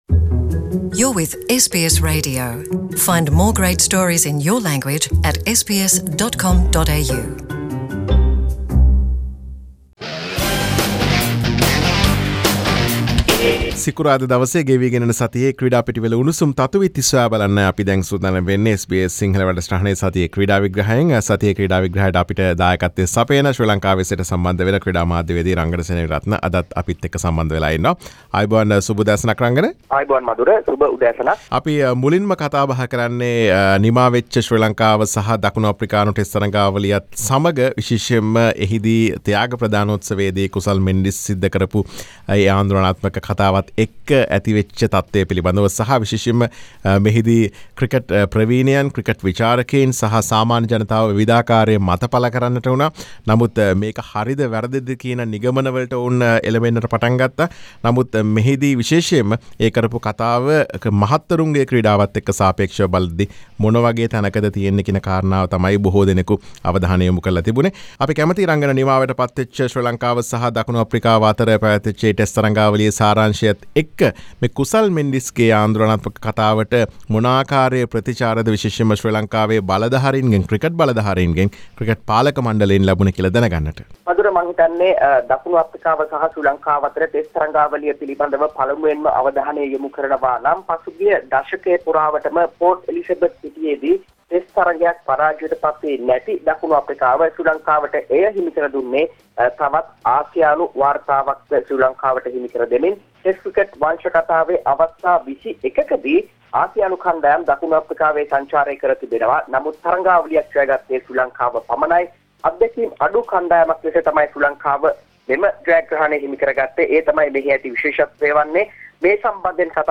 Today’s content: Sri Lanka tour in South Africa, Kusal Mendis’ controversial speech related to Social Media and SLC Chairman’s response, Sanath Jayasuriya’s 2 years ban, Dimuth Karunarathne has been selected to England County Cricket. Sports journalist